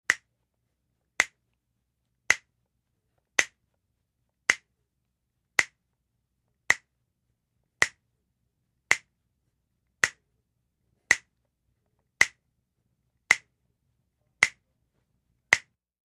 Finger Snaps | Sneak On The Lot
IMPACTS & CRASHES - FIGHTS FINGER SNAPS: INT: Constant 60 BPM snapping.